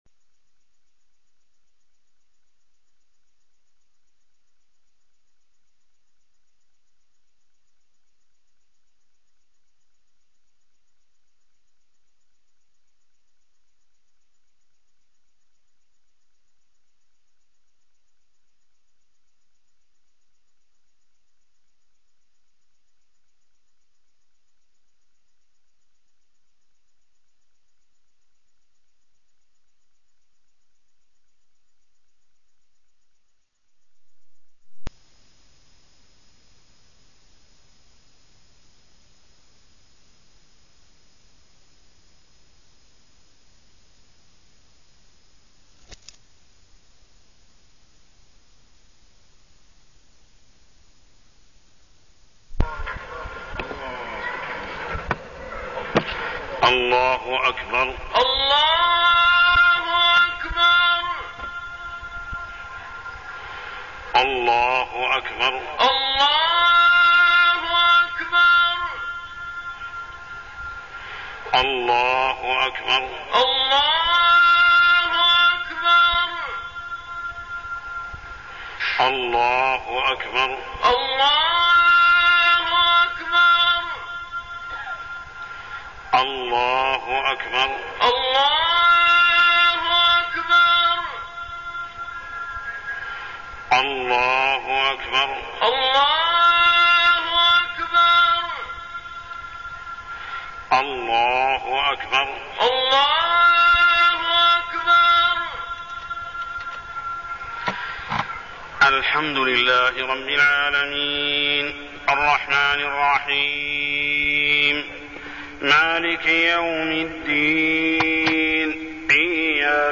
تاريخ النشر ١٨ شعبان ١٤١٥ هـ المكان: المسجد الحرام الشيخ: محمد بن عبد الله السبيل محمد بن عبد الله السبيل التضرع إلى الله